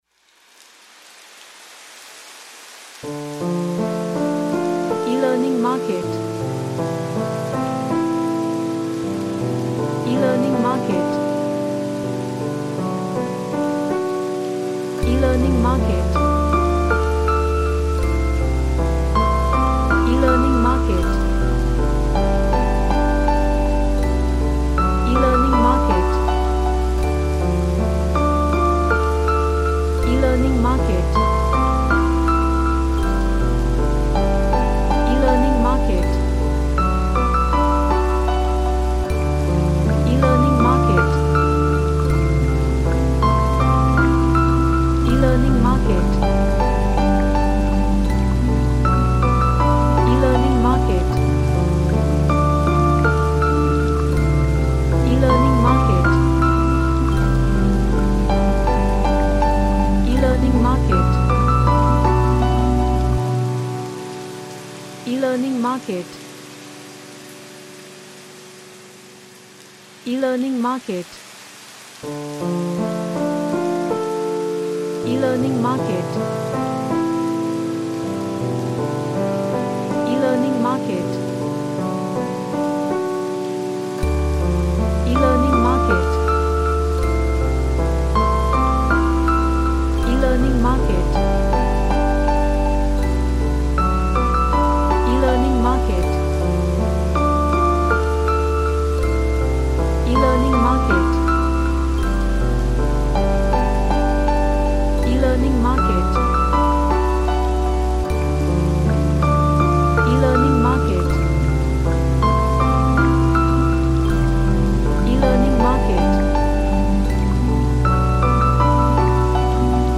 An emotional ambient relaxation track with SFX's
Relaxation / Meditation